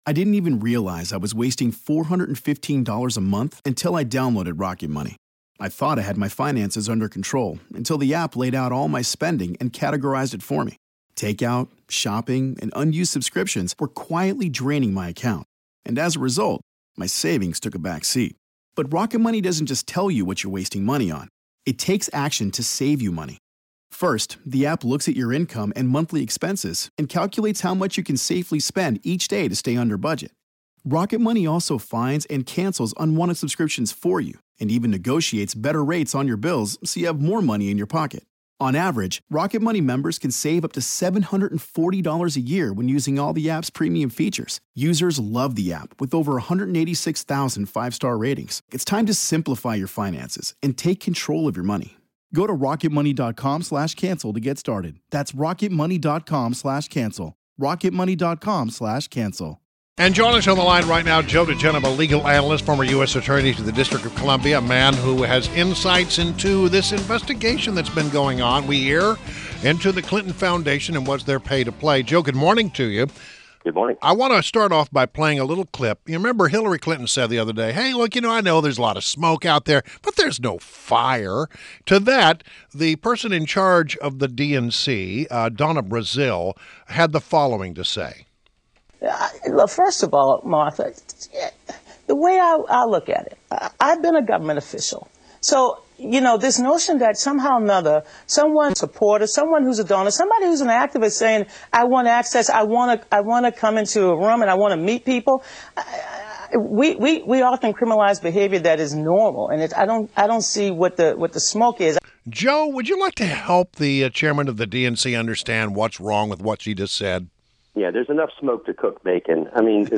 WMAL Interview - JOE DIGENOVA - 08.30.16